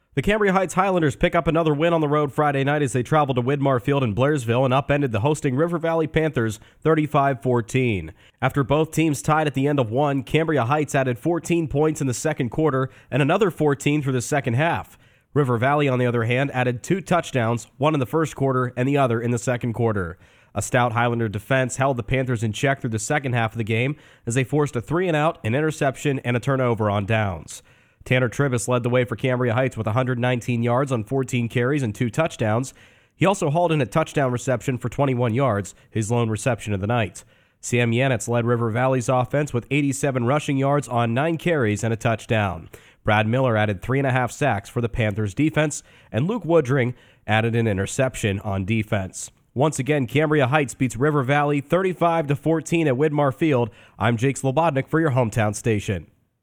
recap of Cambria Heights’ victory on Cat Nation 106.3 FM.